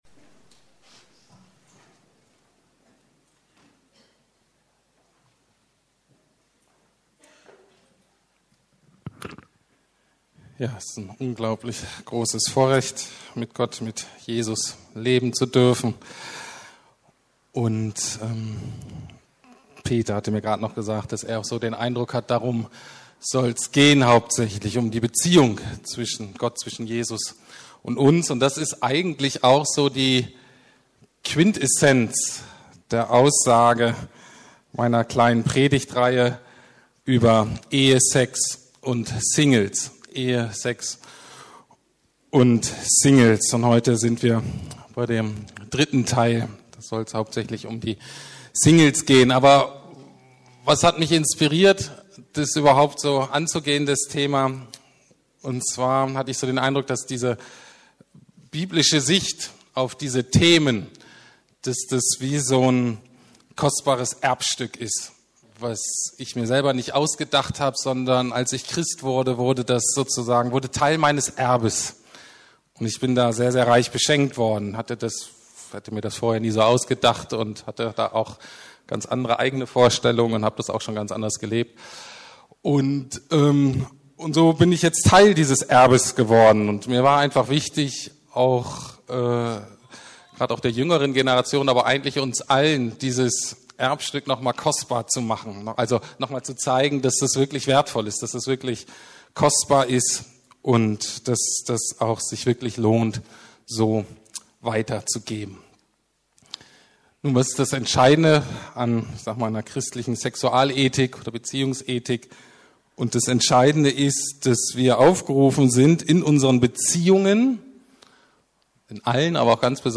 Sex, Singles und Verheiratete 3/3 ~ Predigten der LUKAS GEMEINDE Podcast